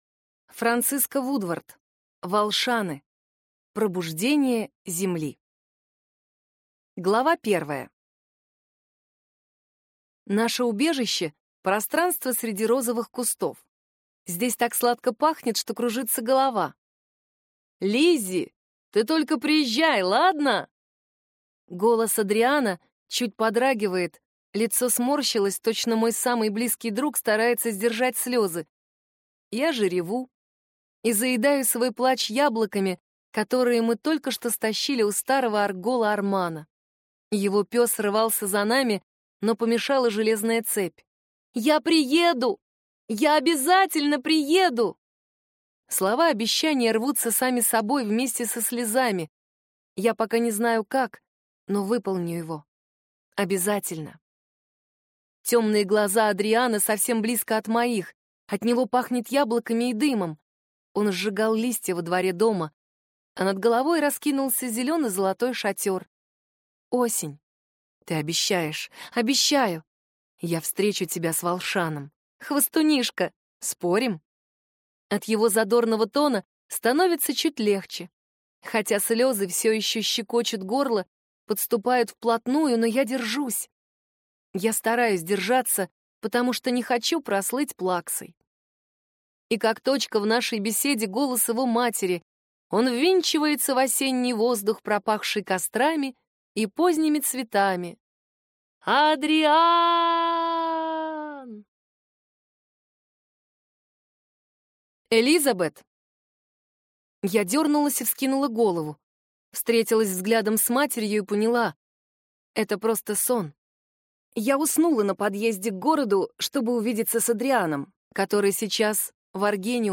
Аудиокнига Волшаны. Пробуждение Земли | Библиотека аудиокниг